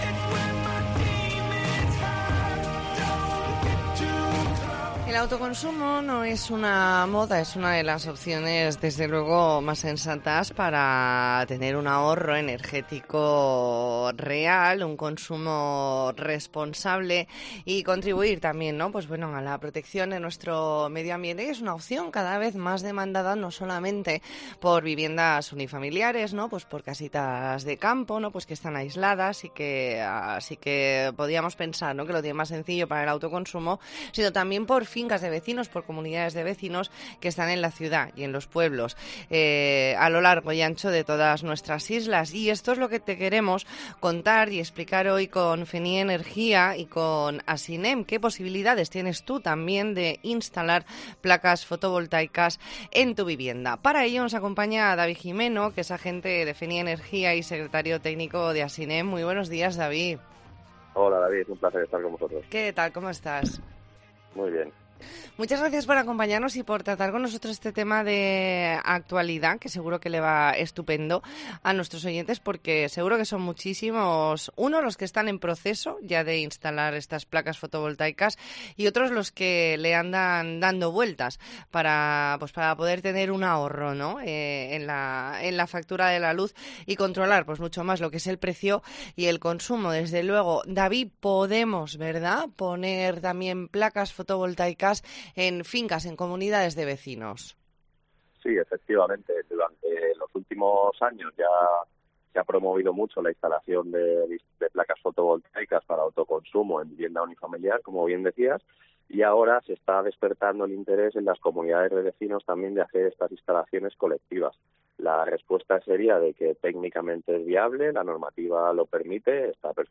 E ntrevista en La Mañana en COPE Más Mallorca, lunes 24 de octubre de 2022.